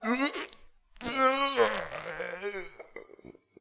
battlemage_die2.wav